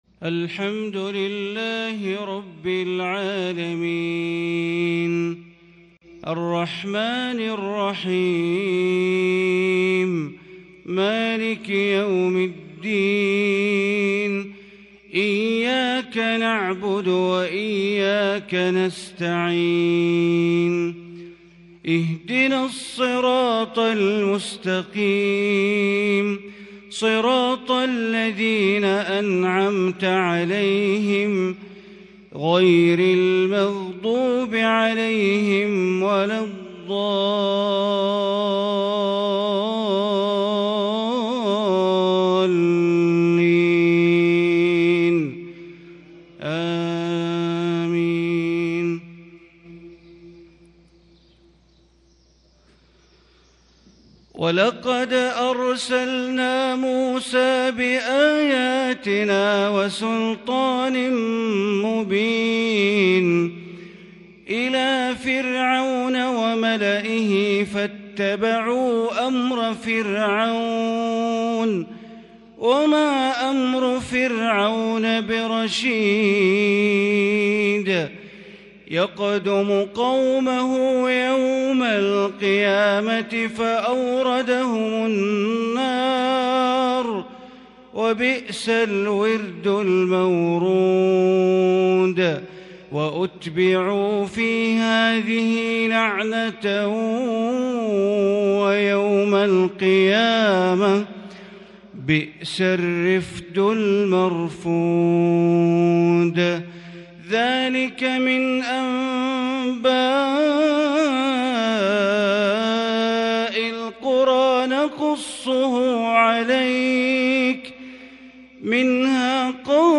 صلاة الفجر 2-4-1442هـ من سورة هود | Fajr prayer from Surah Hud 17/11/2020 > 1442 🕋 > الفروض - تلاوات الحرمين